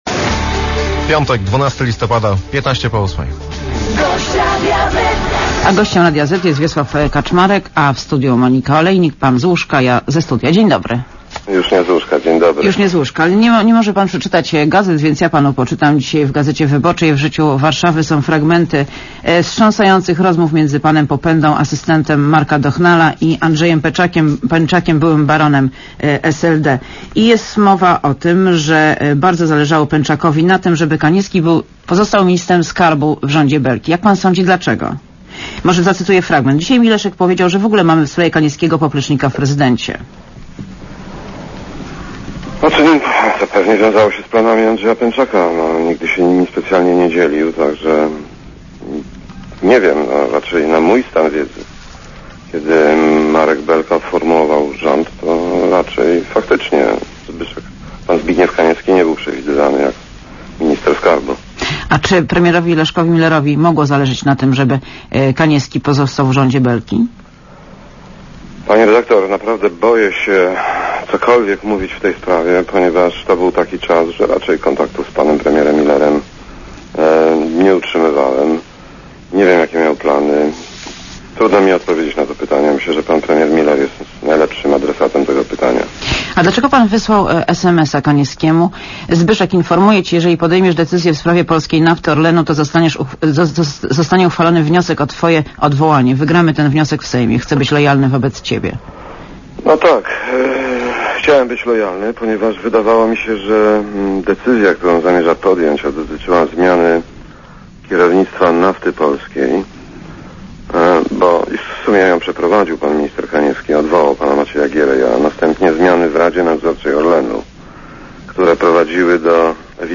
Posłuchaj wywiadu Gościem Radia Zet jest Wiesław Kaczmarek, były minister skarbu.